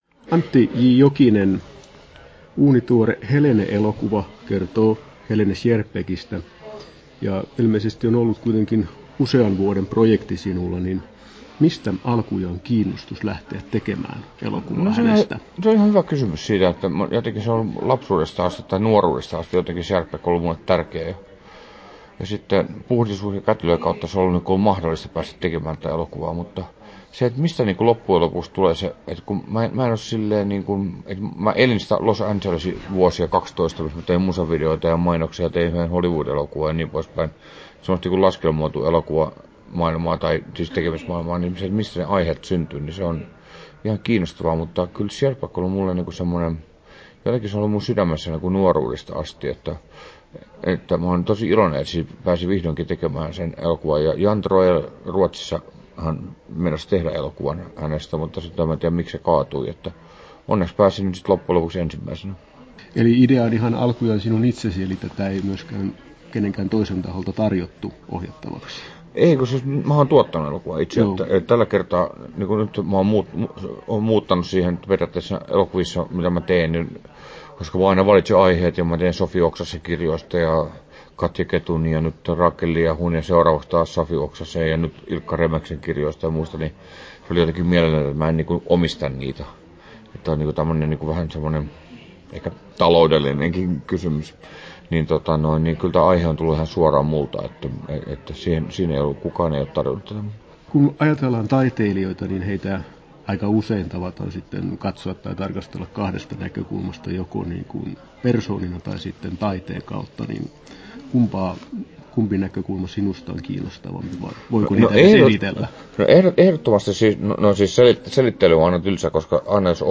Haastattelussa Antti J. Jokinen Kesto: 8'09" Tallennettu: 9.1.2020, Turku Toimittaja